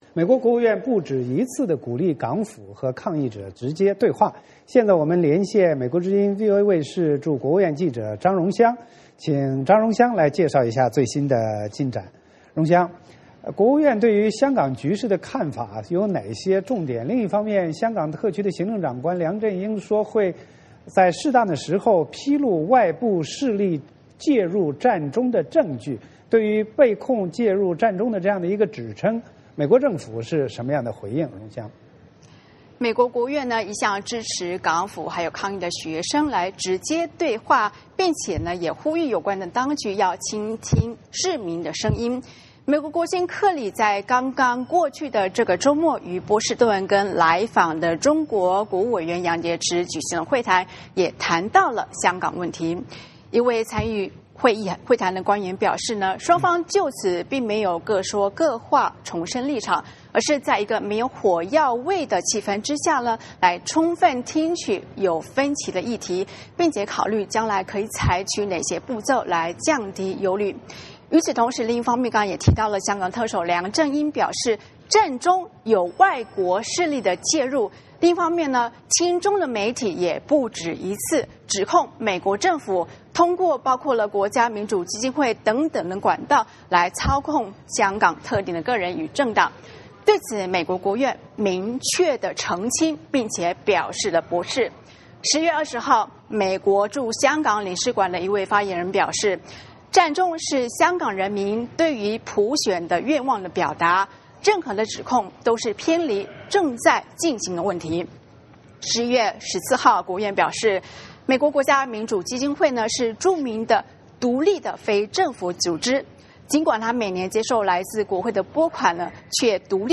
VOA连线：美支持对话 驳斥美国势力介入占中指称